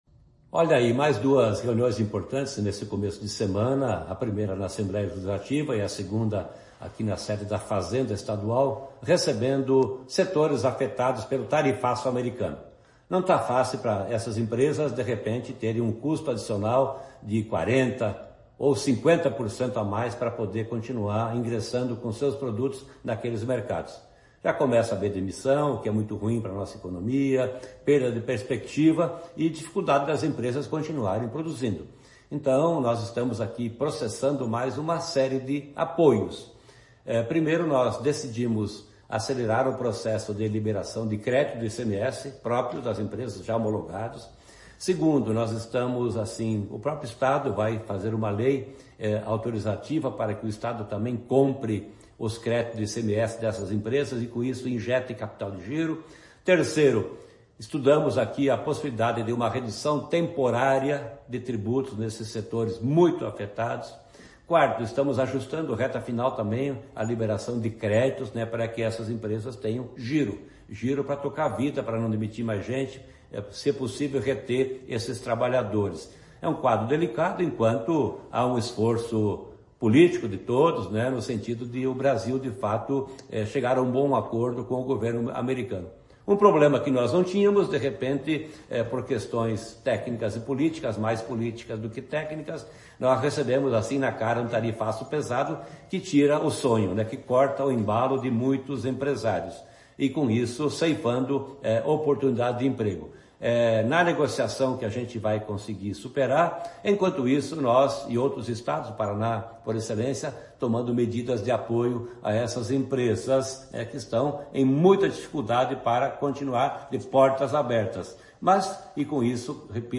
Sonora do secretário da Fazenda, Norberto Ortigara, sobre os novos auxílios para empresas afetadas pelo tarifaço